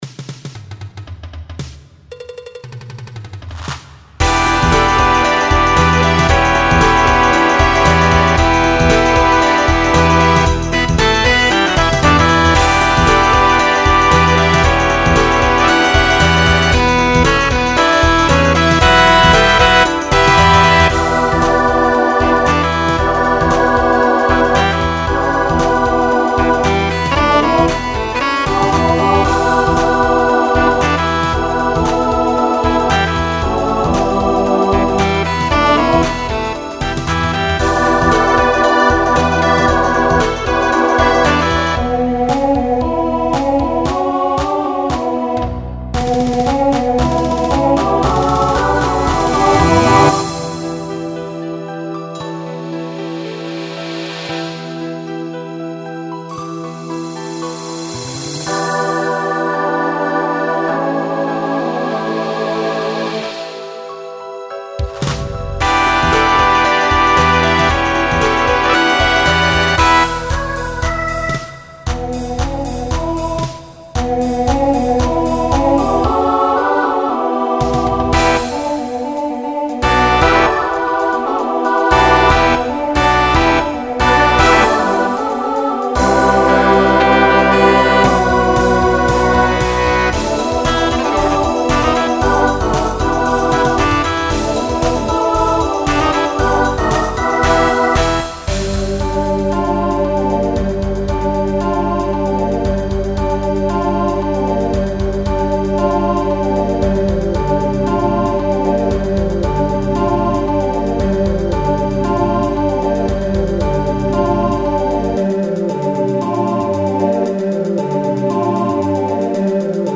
Voicing SATB